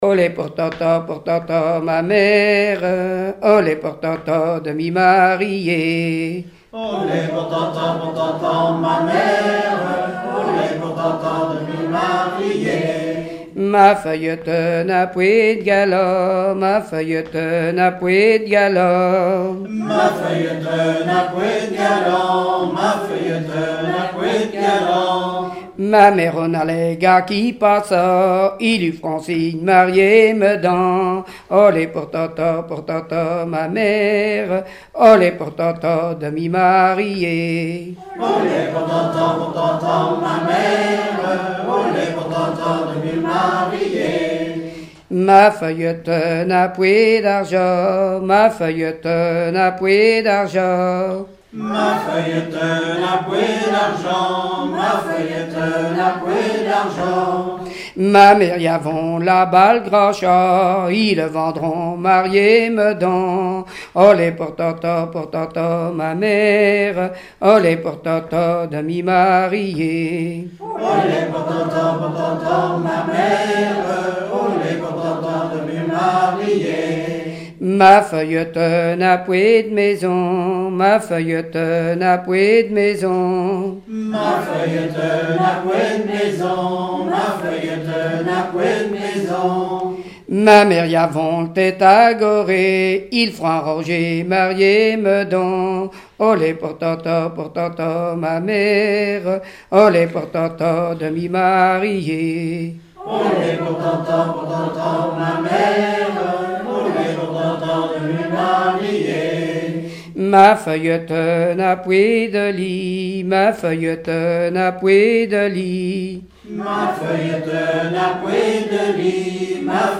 Genre dialogue
collectif de chanteurs du canton
Pièce musicale inédite